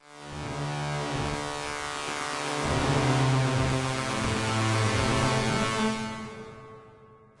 描述：这是一台老式的Boss鼓机，通过Nord Modular补丁。一个小节，78BPM.
标签： 数字 808 环路 噪声 跳动
声道立体声